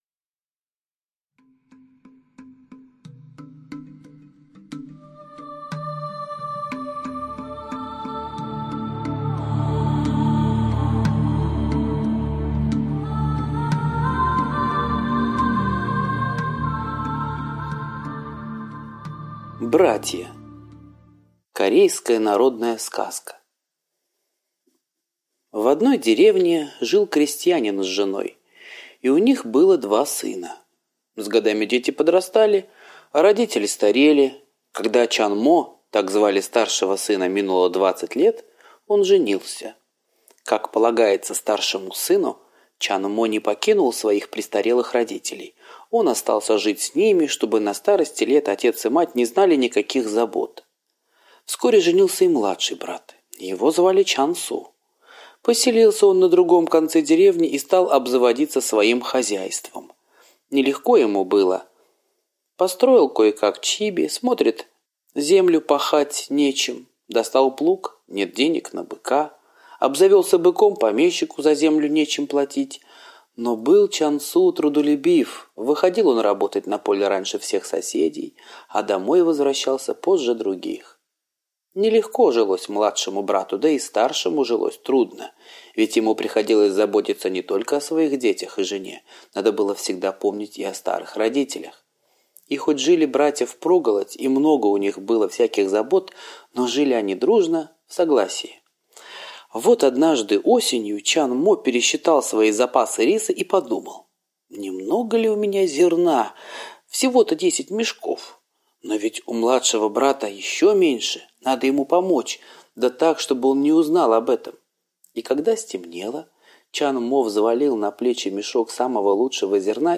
Братья – азиатская аудиосказка